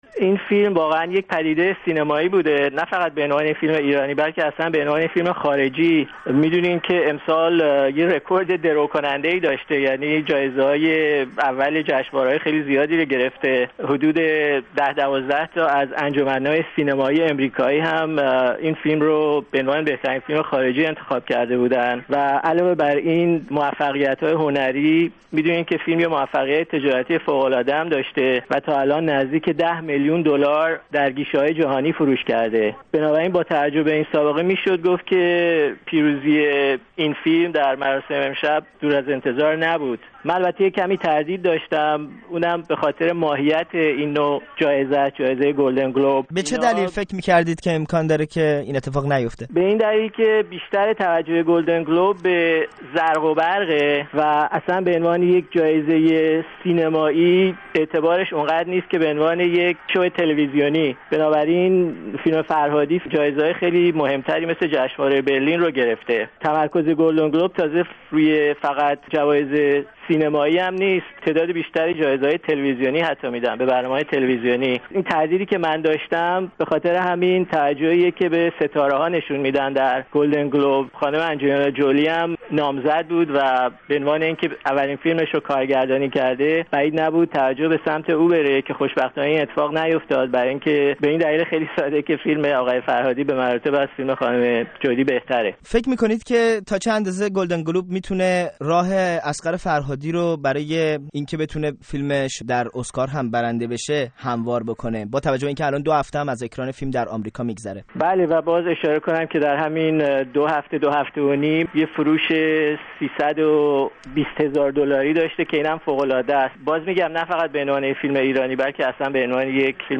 گفت و گو